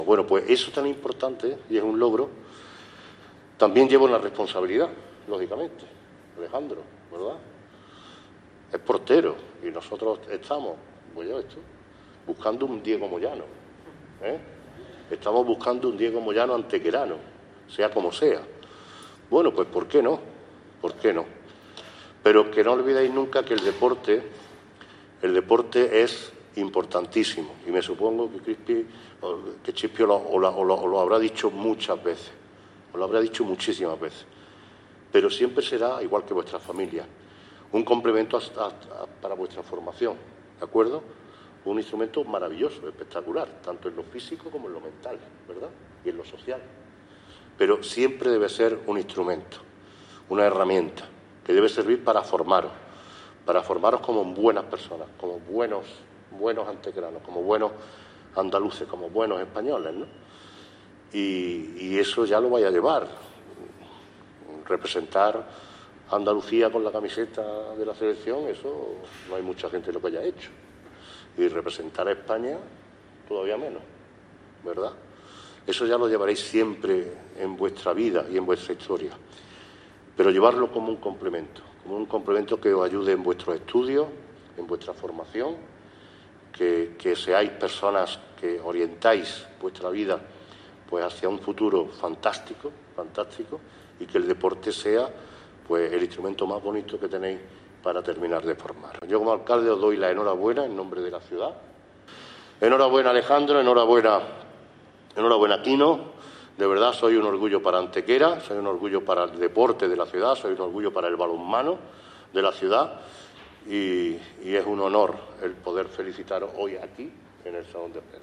El alcalde de Antequera, Manolo Barón, y el teniente de alcalde delegado de Deportes, Juan Rosas, han presidido en la tarde de este martes una recepción en el Salón de Plenos a jugadores y entrenadores de balonmano en nuestra ciudad que han cosechado recientemente éxitos destacados en competiciones nacionales e internacionales.
Cortes de voz